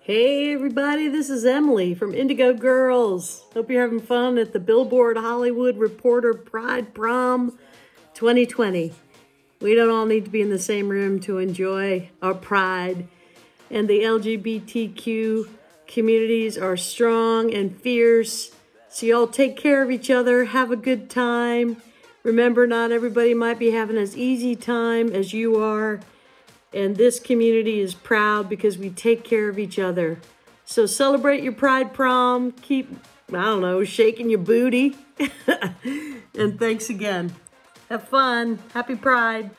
(captured from the webcast)